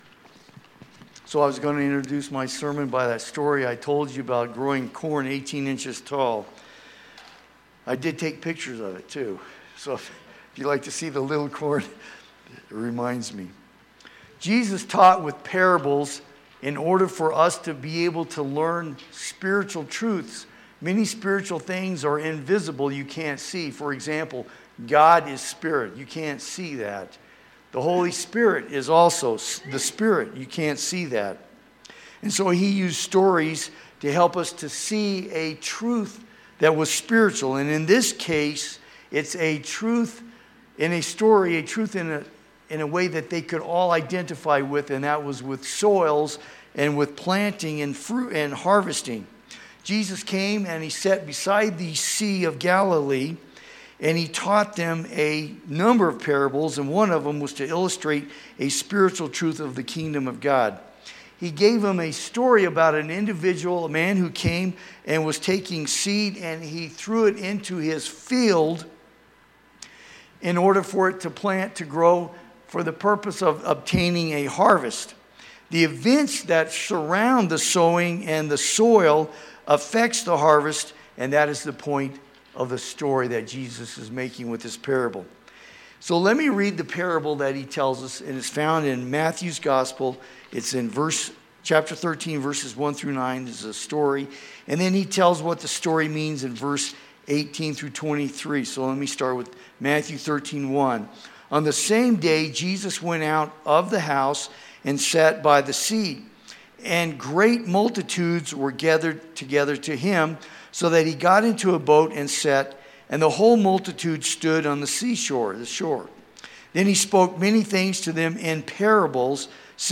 East Valley Baptist Church of Flagstaff, Arizona - Sound files of Sunday Message